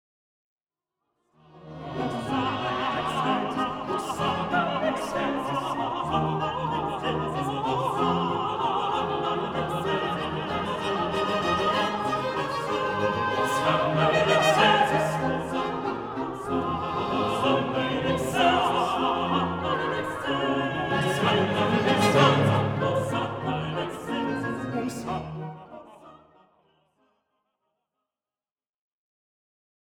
Version pour ensemble de solistes